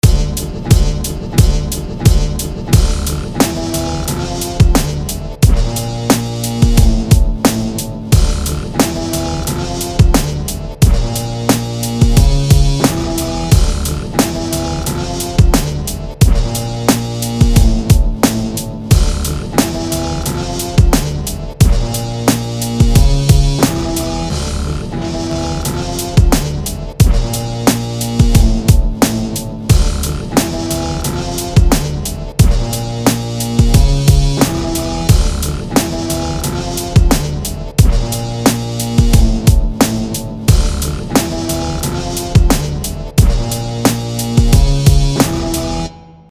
드럼이 샘플에 더 잘 어우러지는 느낌을 낼 수 있는 믹싱을 해보셔도 좋을것 같습니다.